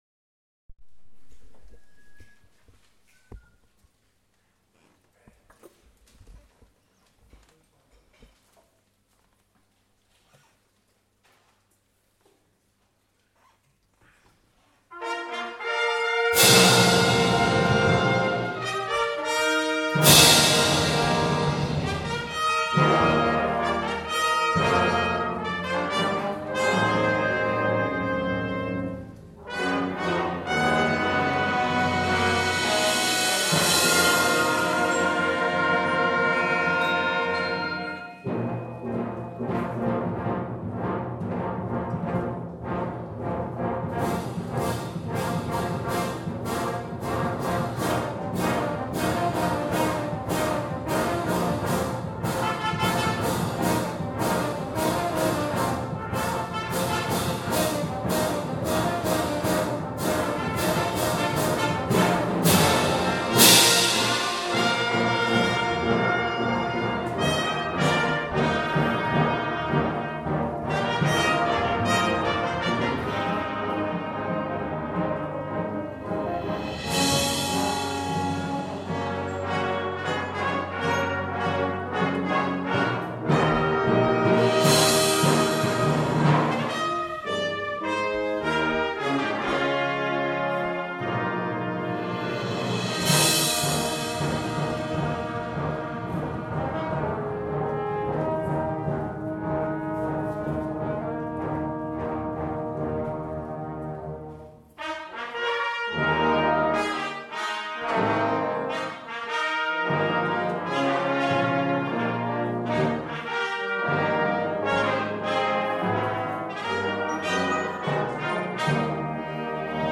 Brass Ensemble - Olympia
A Concert of Wind, Brass and Percussion, April 2015